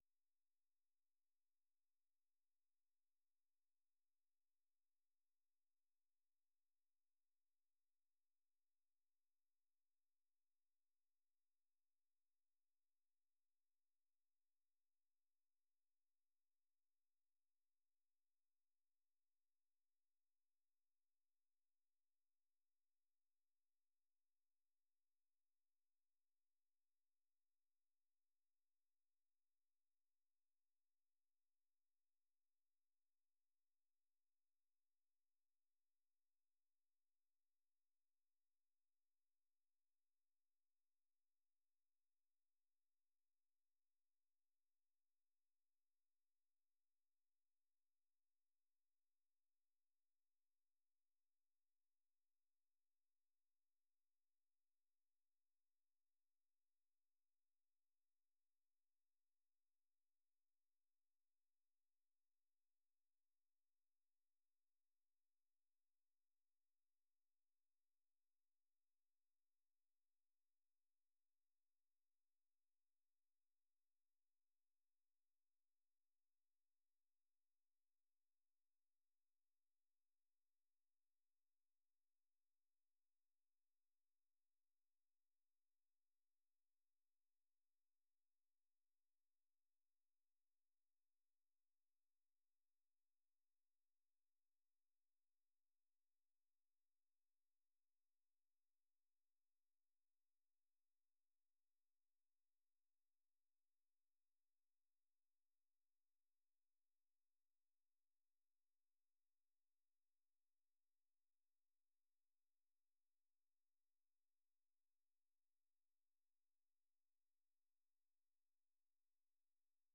ዐርብ፡-ከምሽቱ ሦስት ሰዓት የአማርኛ ዜና